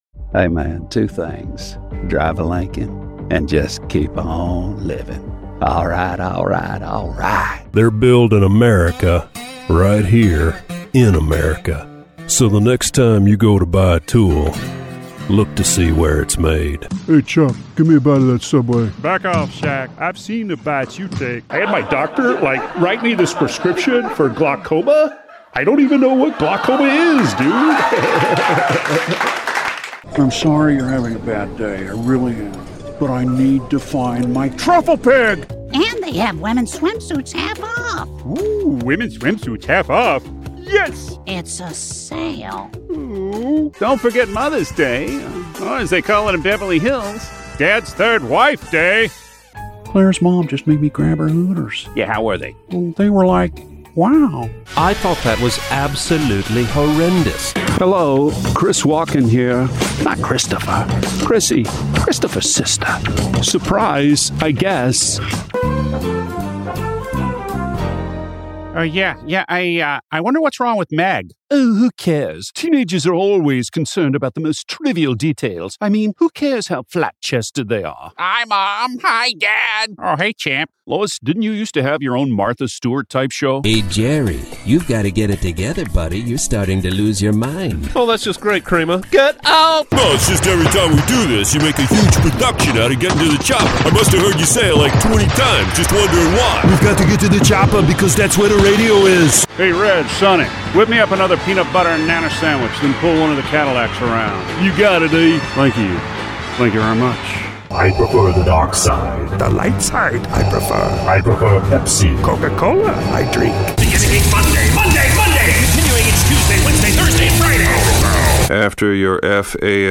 Naturelle, Distinctive, Polyvalente, Amicale, Chaude